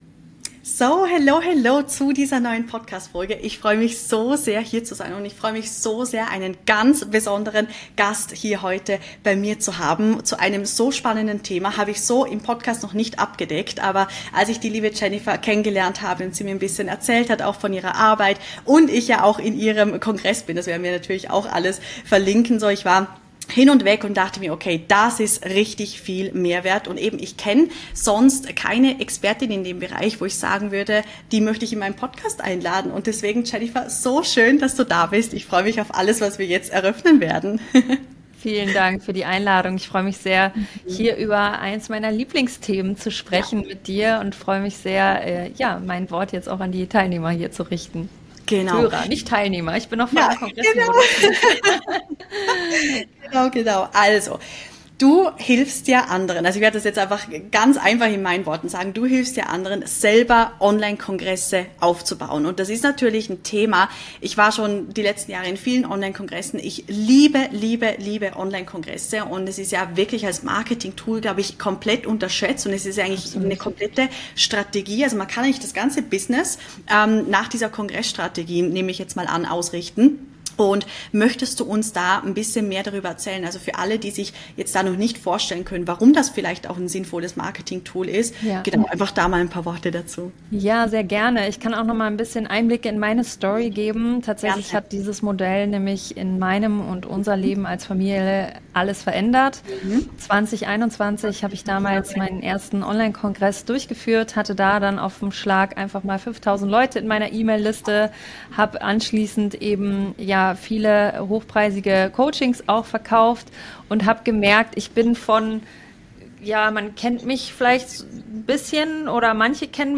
#167 Online Kongresse als Kundengewinnungs-Strategie - Interview